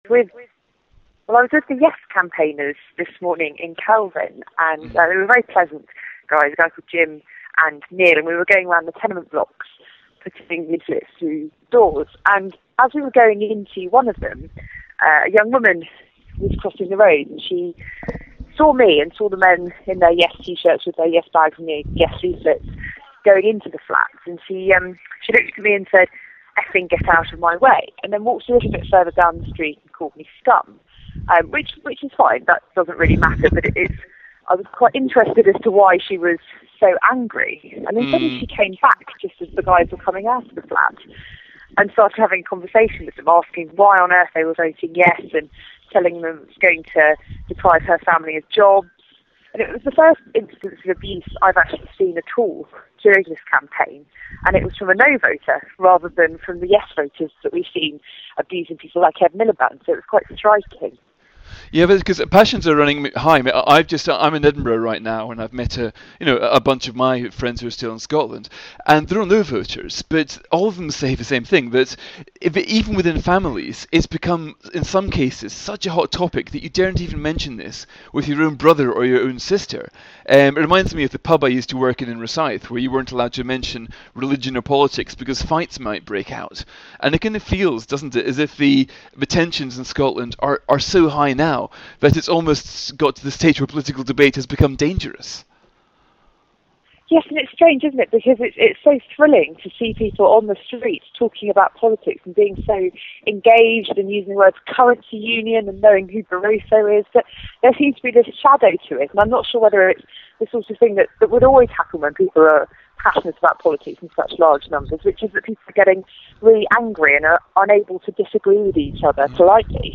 While joining 'yes' campaigners in Glasgow Kelvin, 17 Sep 2014